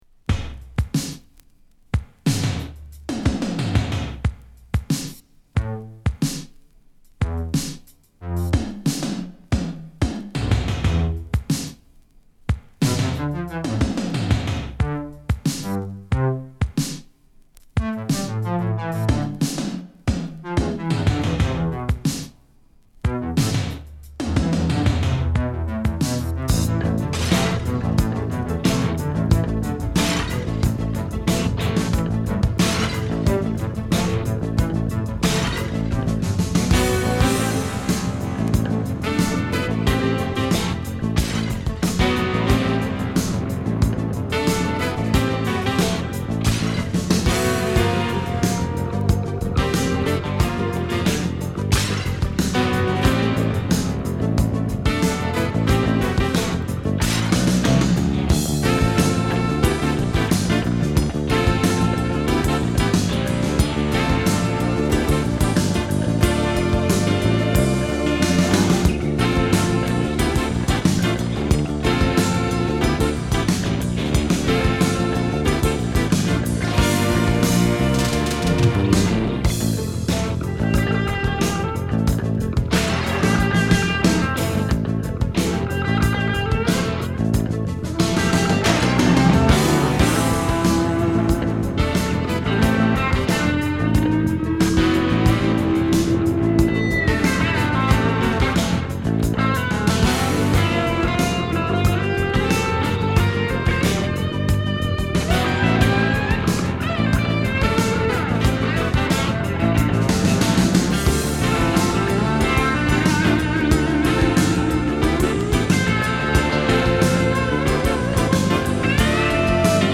エレクトリックなサウンドが特徴の1枚で
爽やかフュージョン／AORからファンクまでを収録！